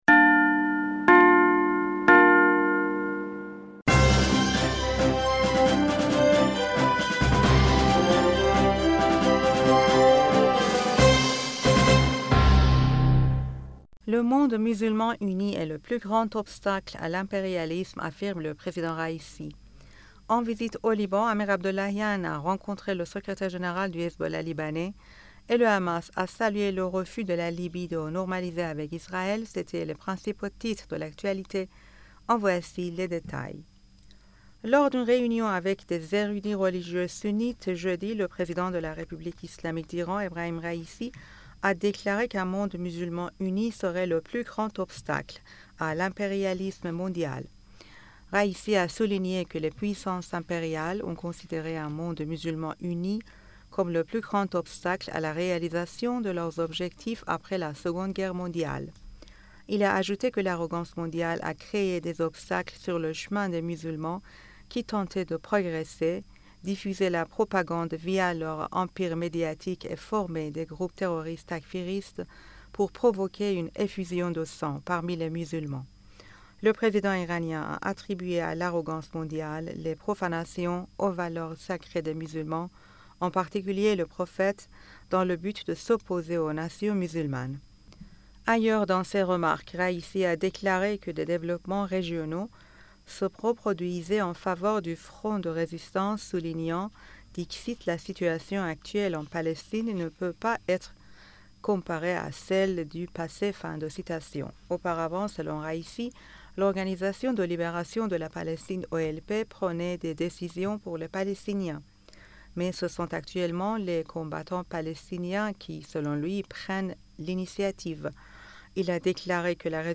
Bulletin d'information du 01 Septembre 2023